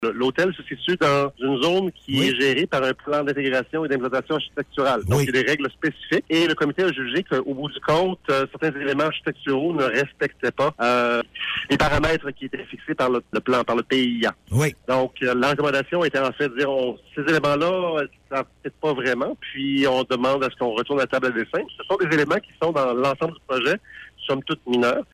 Le maire, Guy Caron, a expliqué sur nos ondes que l’Hôtel Rimouski est situé dans un secteur où il existe un plan particulier d’urbanisme.